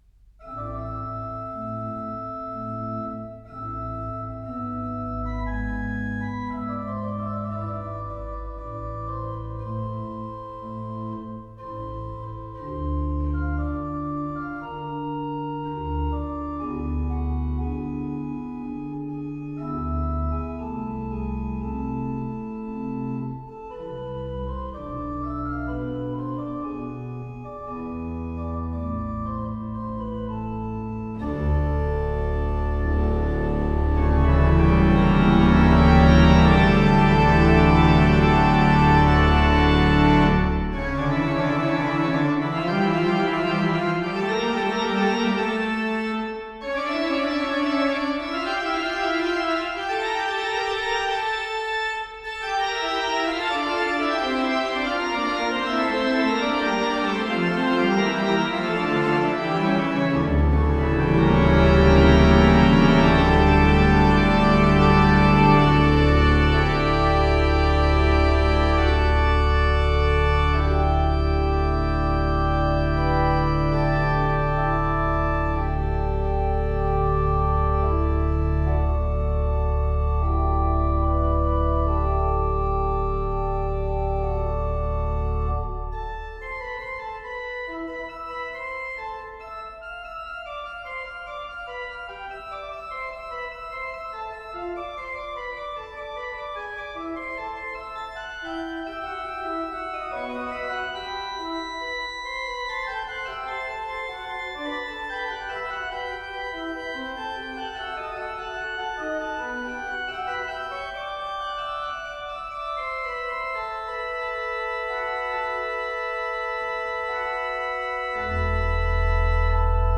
12.-29.-Orgelmusikken-til-filmen-LYDBØLGE.wav